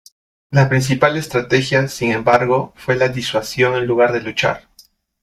Pronounced as (IPA)
/ˈsin/